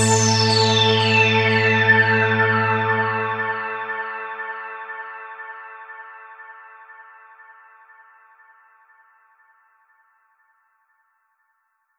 Synth 37.wav